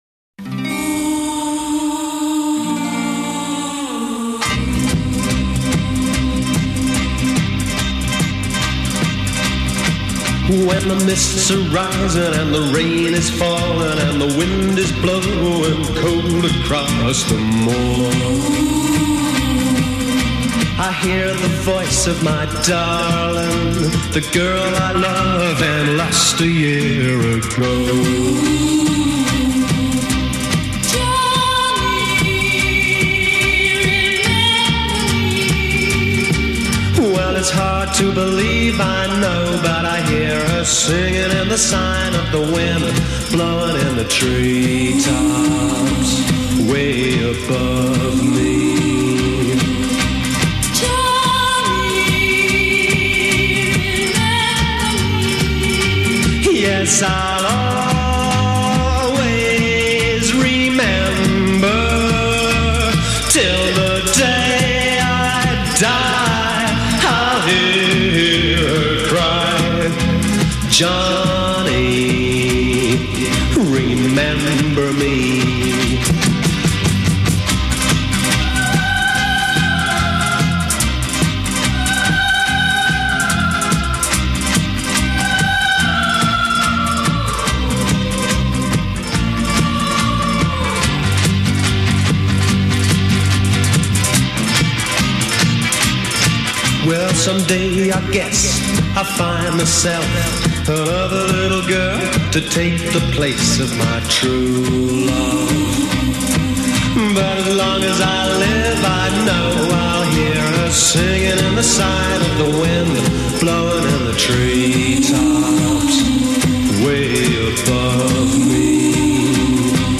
vocals
drums
guitar
bass
piano
backup vocals
verse 3     Instrumental with vocalise